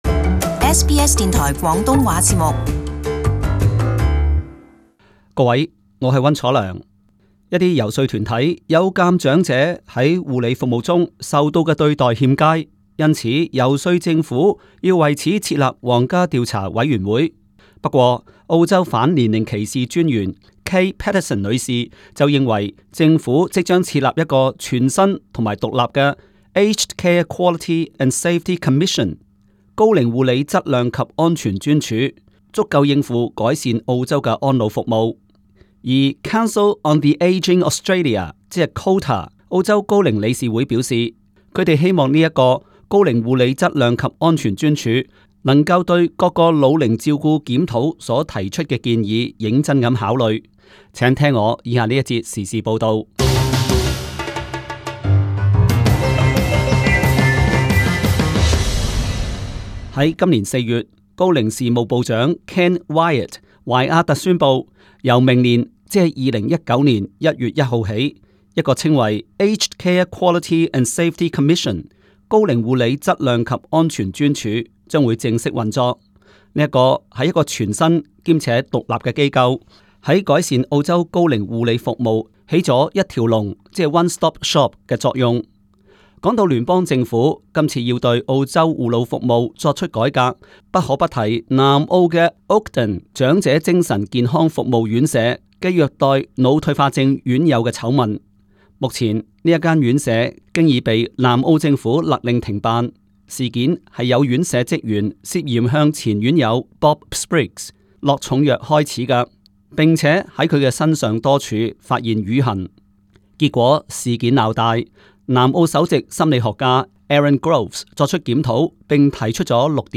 【时事报导】 反年龄歧视专员：新专署能改善护老服务